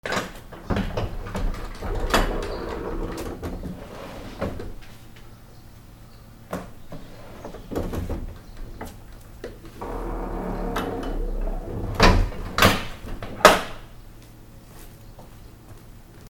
扉
/ K｜フォーリー(開閉) / K05 ｜ドア(扉)
『カタガタ ギー』